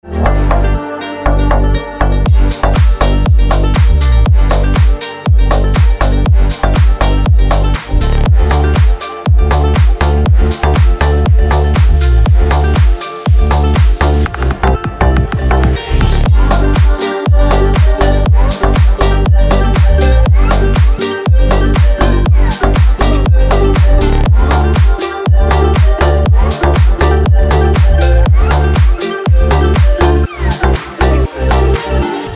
Клубные Рингтоны » # Рингтоны Без Слов
Рингтоны Ремиксы » # Танцевальные Рингтоны